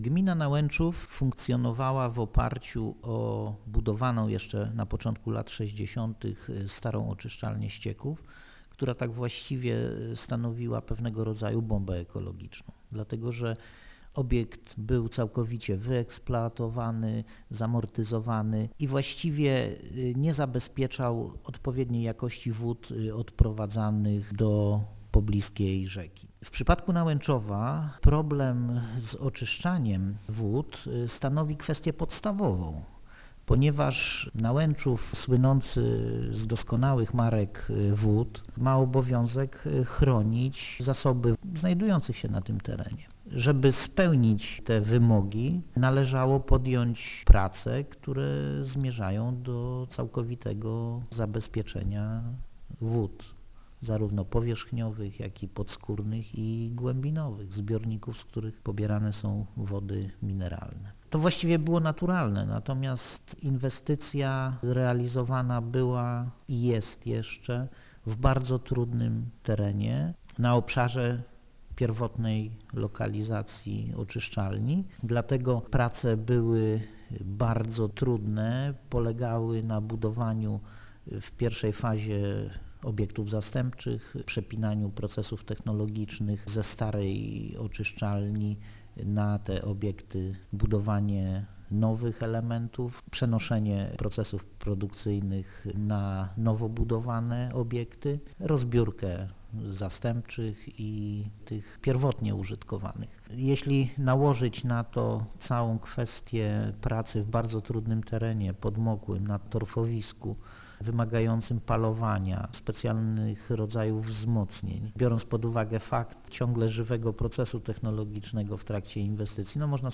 - Realizacja tej inwestycji była konieczna - podkreśla zastępca burmistrza Nałęczowa Artur Rumiński